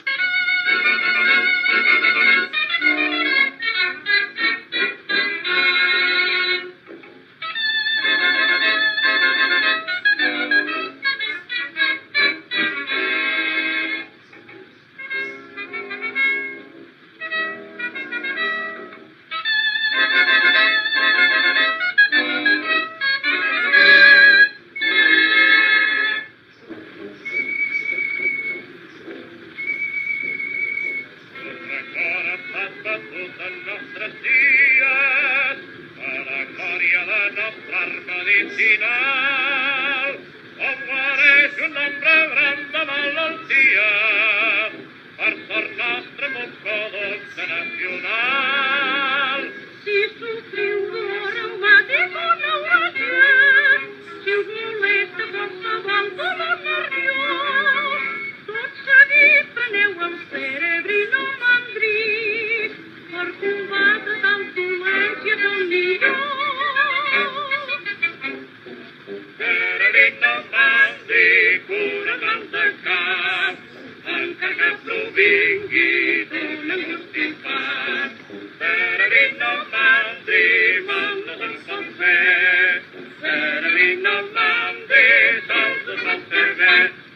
cantada a ritme de sardana